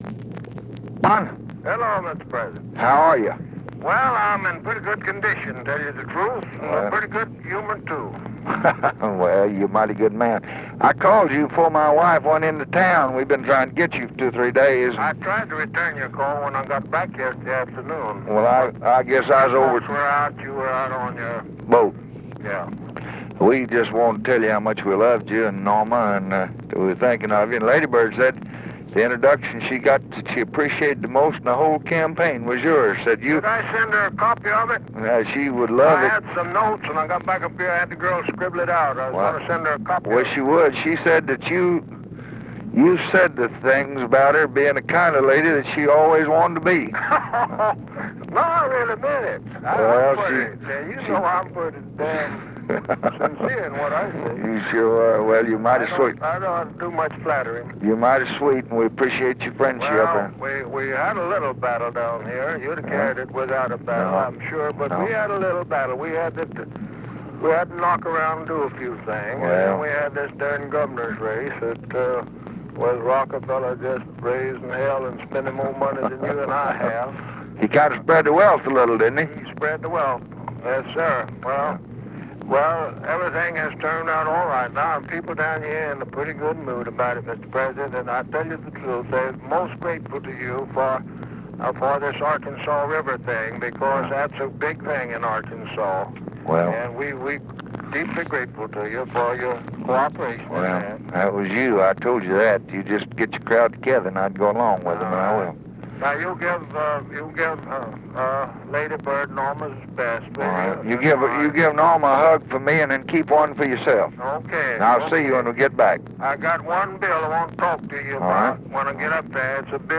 Conversation with JOHN MCCLELLAN, November 9, 1964
Secret White House Tapes